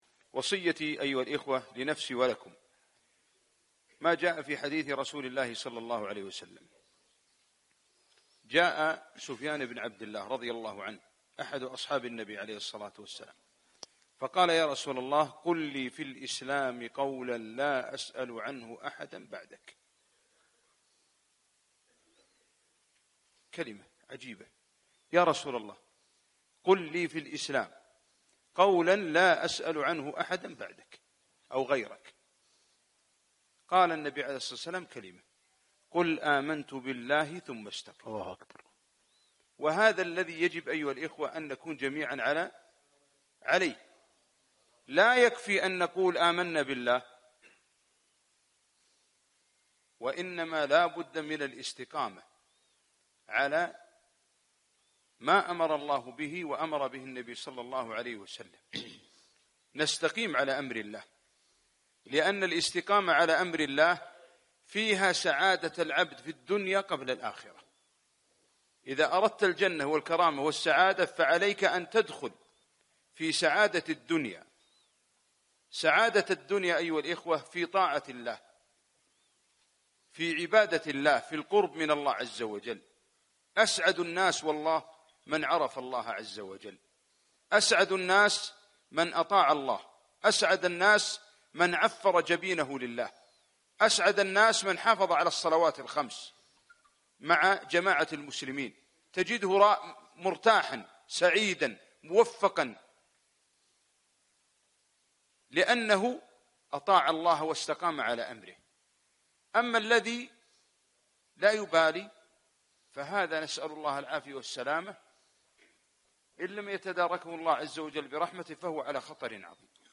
قل آمنت بالله ثم استقم - كلمة